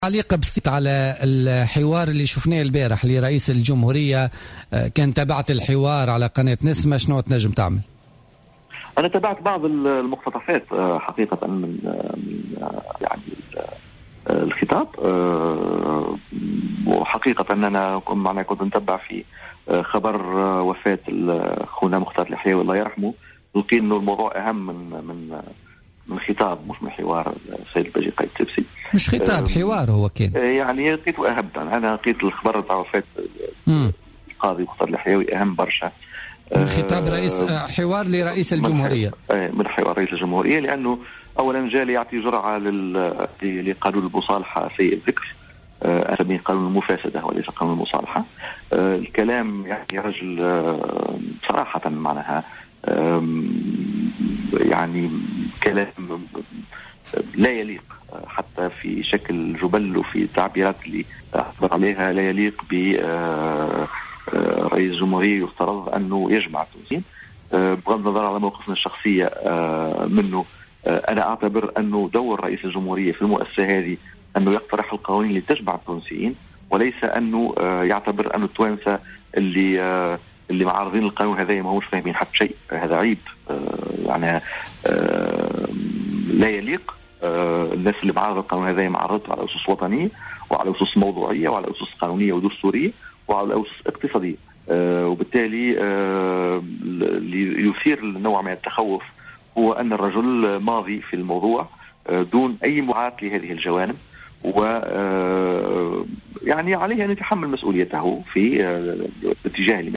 واعتبر منصر في مداخلة هاتفية خلال حصة بوليتيكا اليوم الاربعاء،